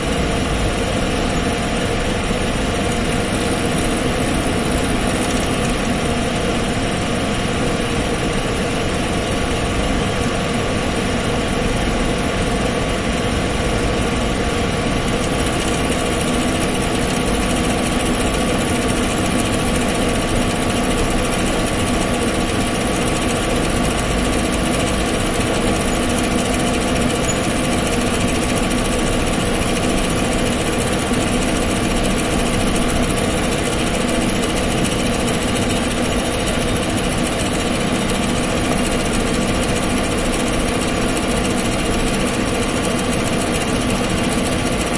描述：旋转绳索在空气中。
Tag: 空气 打开 绳索